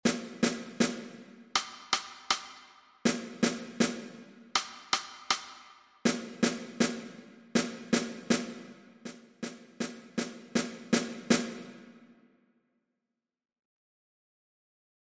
O segundo corte comeza cun xogo de eco entre o son grave do instrumento e o máis agudo producido ao golpear no bordo.
É importante que todos os instrumentos comecen suave no último compás e aumenten gradualmente a intensidade ata chegar a última negra fortissimo.
Son_corte_2_cresc..mp3